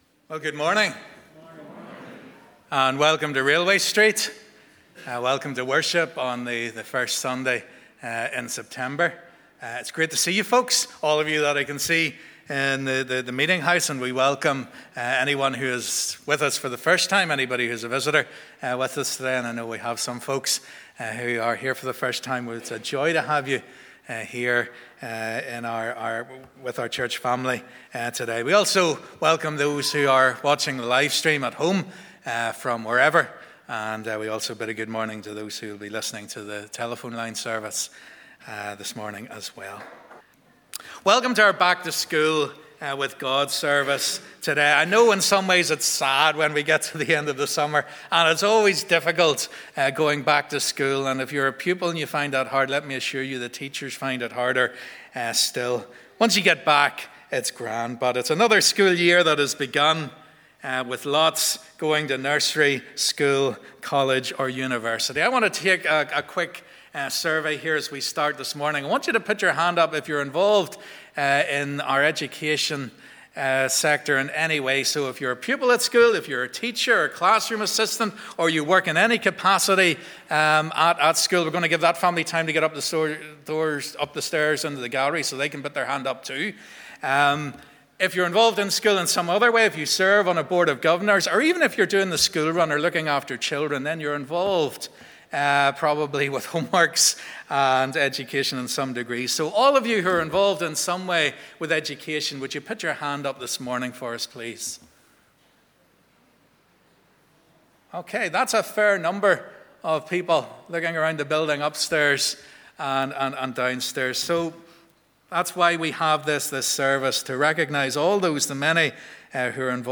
Welcome to our Back to School with God service.